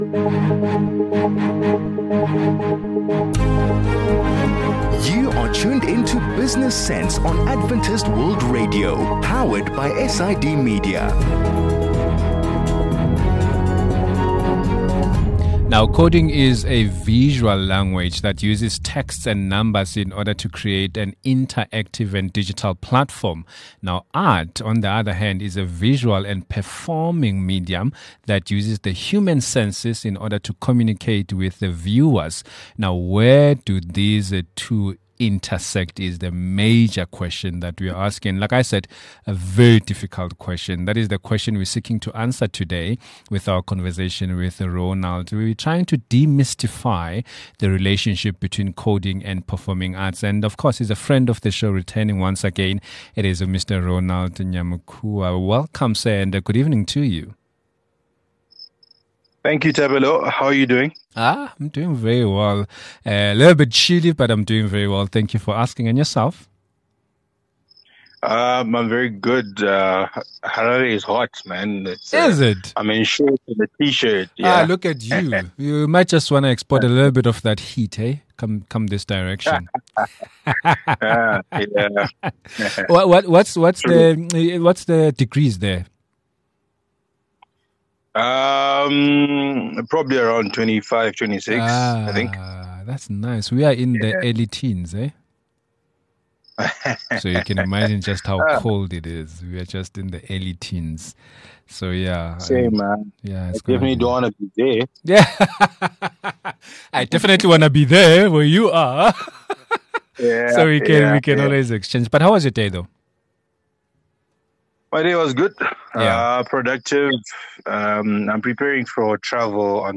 Where do the two intersect? That is the question we seek to answer in today’s conversation about demystifying the relationship between coding and performing arts.